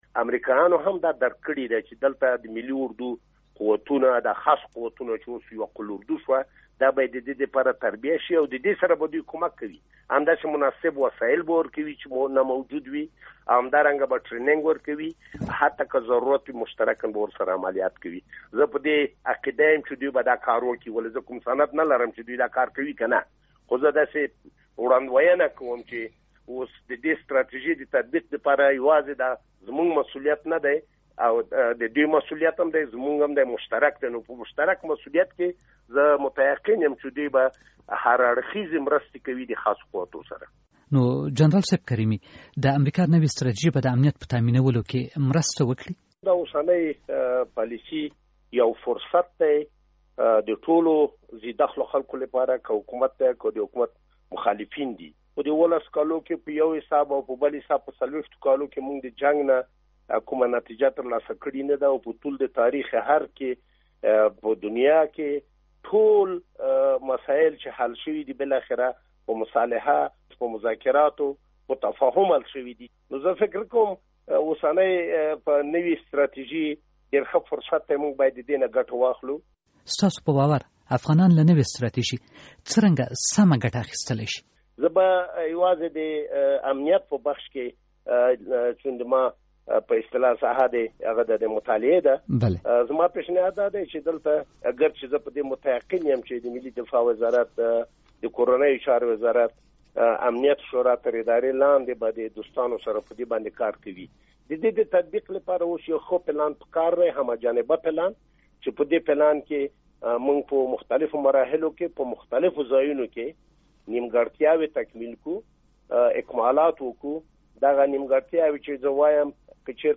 مرکه
له جنرال شېرمحمد کریمي سره مرکه